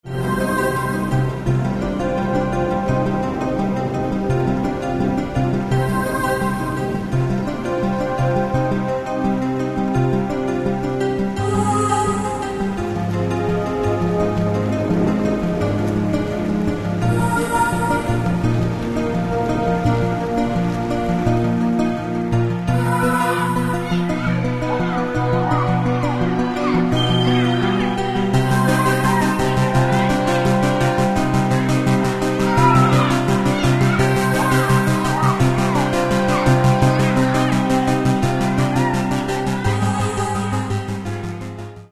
Каталог -> Классическая -> Нео, модерн, авангард
Внутри нее – просторно и легко.